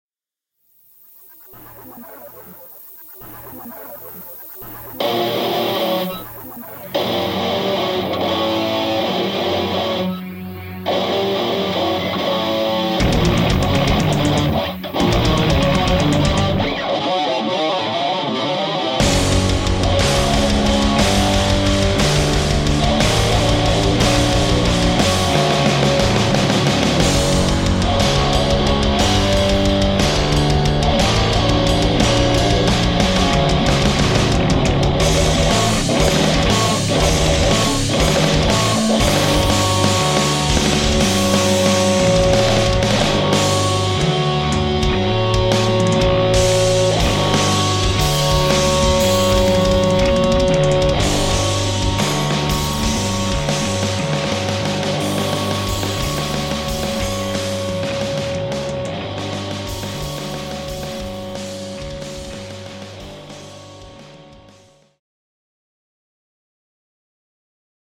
In this relatively easy-mix metal guitar example there is no side-chain ducking and no overall reverb.
The weird sound at the edge of the tune (synth pad) is from the free Zystrix Pro Audio Samples #148.
Zystrix Recording Metal Guitar - full band.mp3